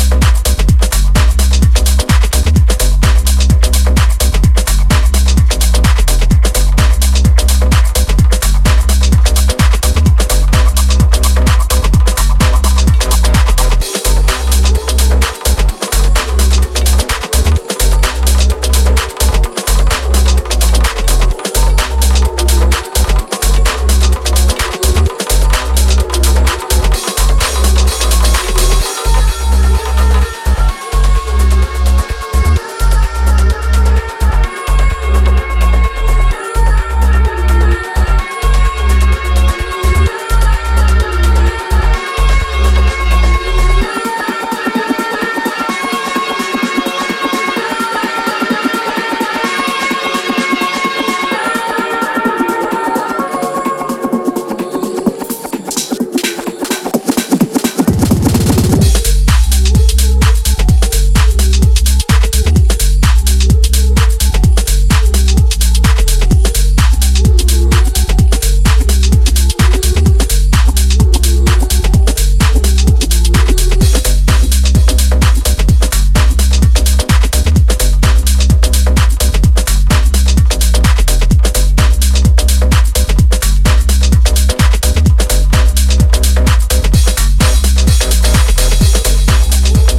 ジャンル(スタイル) DEEP HOUSE / TECH HOUSE / MINIMAL